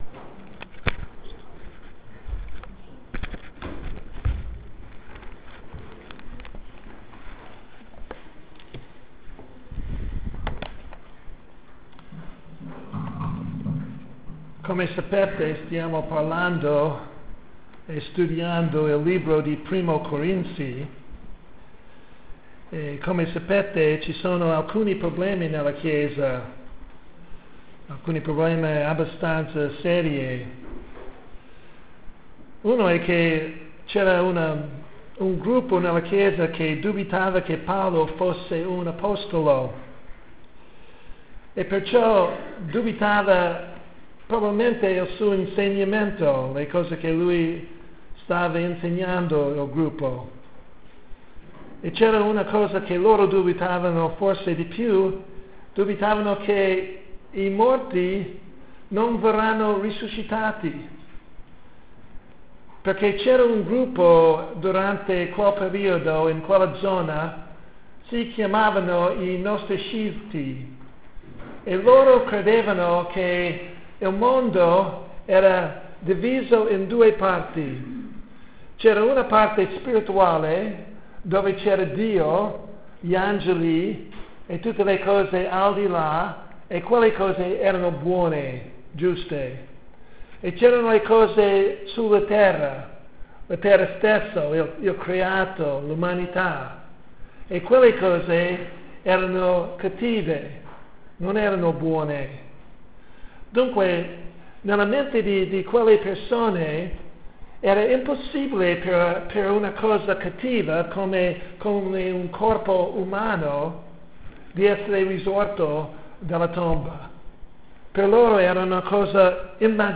Predicazione